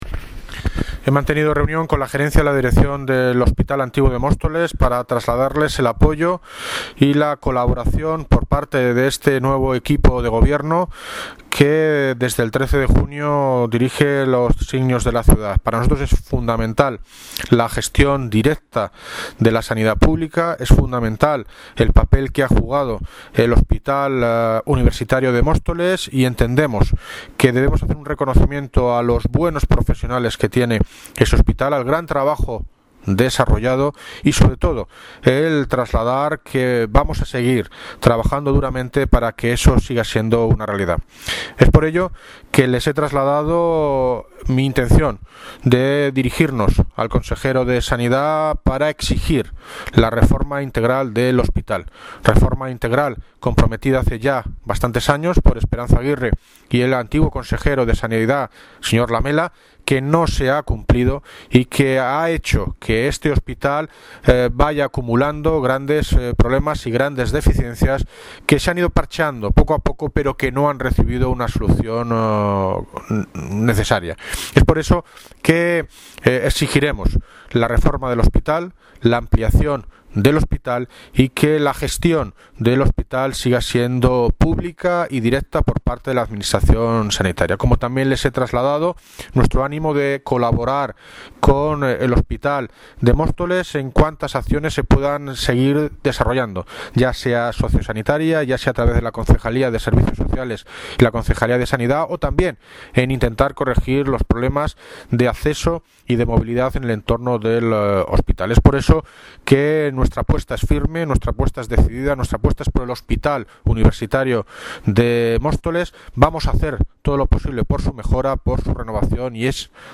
Audio - David Lucas (Alcalde de Móstoles) reunión con la gerencia del Hospital